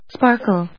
音節spar・kle 発音記号・読み方
/spάɚkl(米国英語), spάːkl(英国英語)/